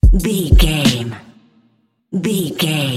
Aeolian/Minor
drum machine
synthesiser
electric piano
hip hop
Funk
neo soul
acid jazz
confident
energetic
bouncy
Triumphant
funky